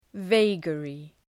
Προφορά
{və’geərı}